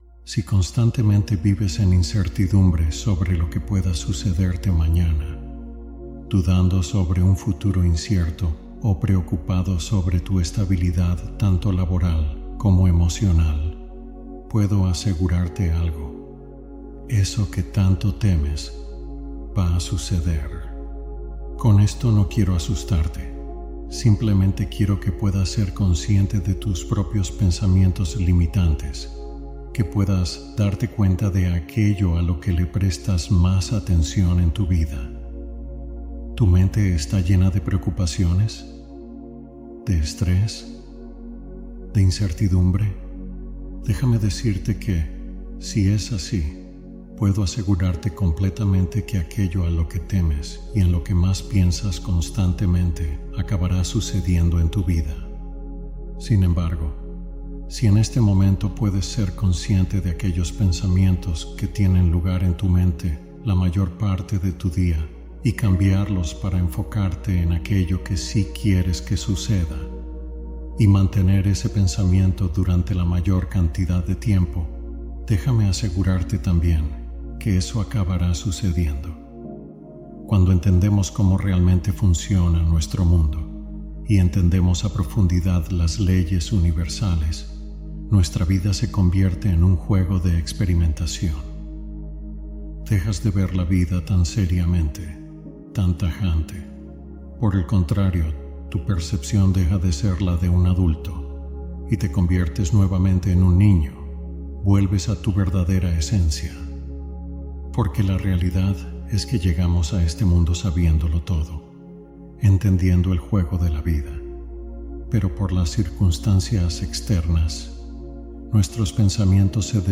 Meditación y afirmaciones como acompañamiento del descanso